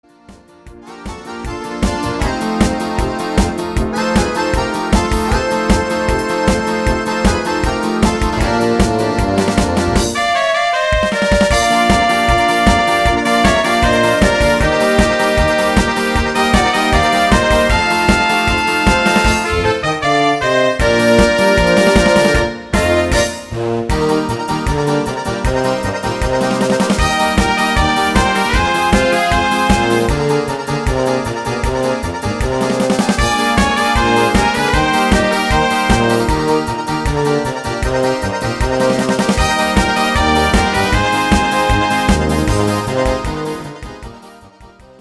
instr. Polka